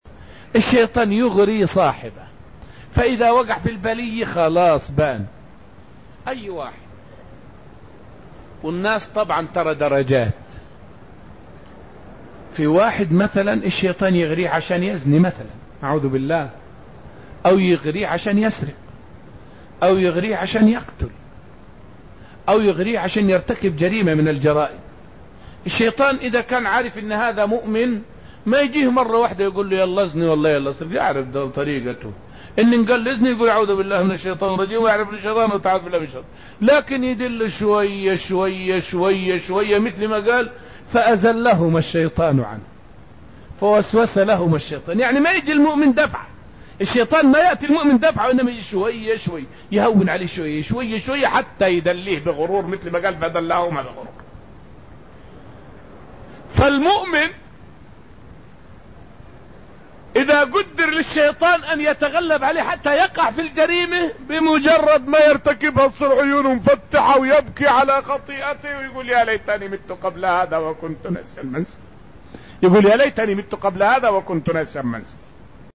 فائدة من الدرس العاشر من دروس تفسير سورة الحشر والتي ألقيت في المسجد النبوي الشريف حول حيلة الشيطان في إيقاع المؤمن بالمعصية.